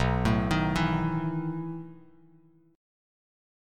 B11 Chord
Listen to B11 strummed